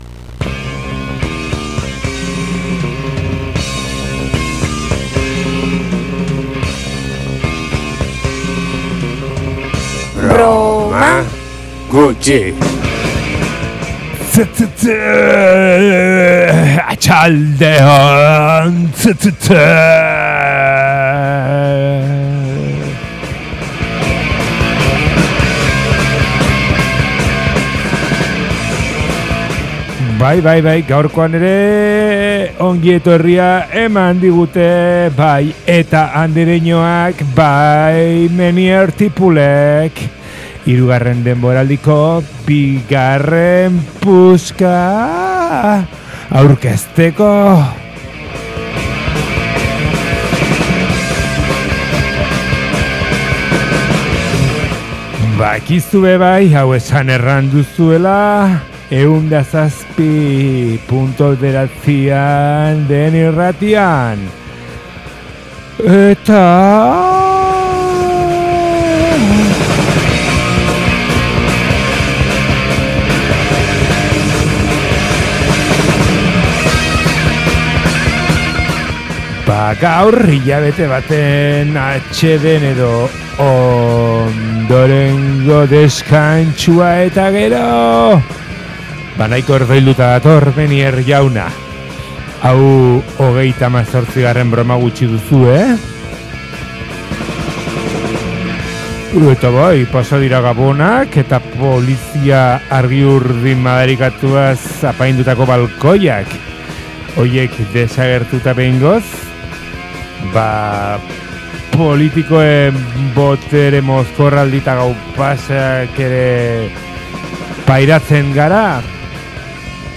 Urtez aldatuta ere, hardcore eta punk doinuek segitzen dute irratsaio honetan, hausnarketa desberdinekin uztartuta.